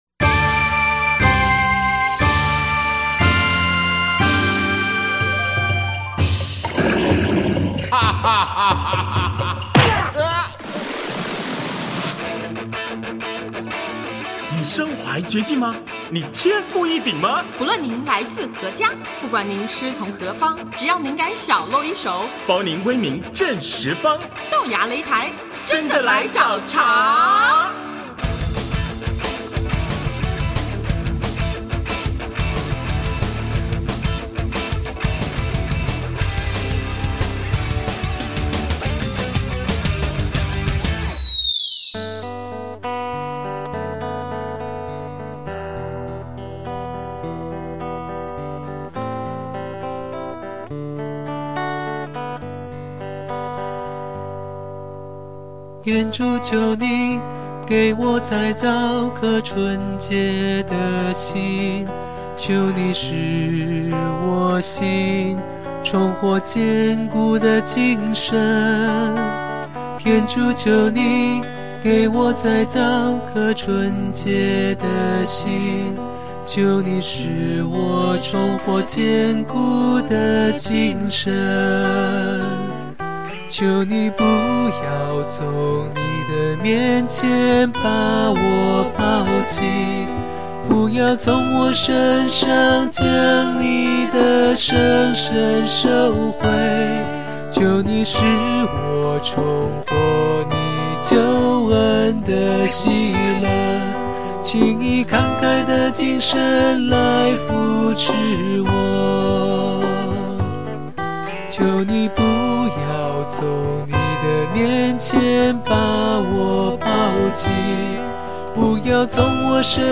【豆芽擂台】185|专访香柏之歌(一)：感动更多的人